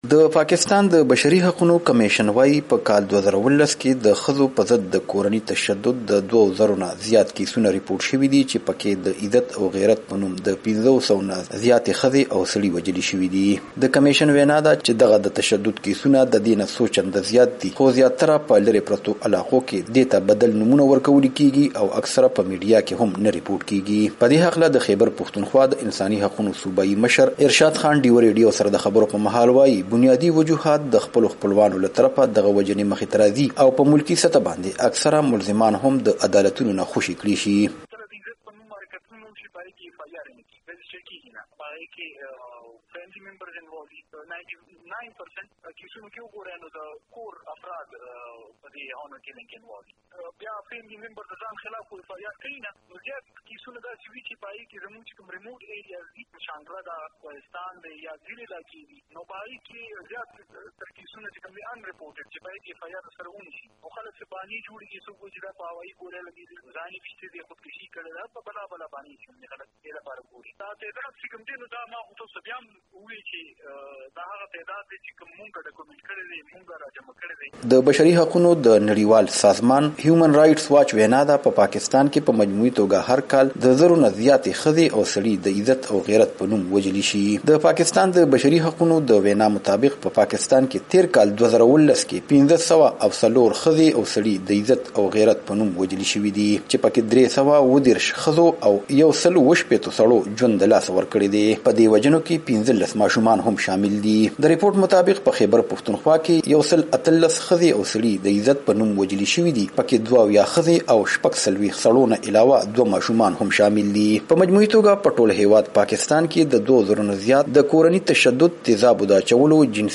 رپورټ: په پاکستان کې د ٥٠٠ نه زیات کسان د غیرت په نوم وژل شوي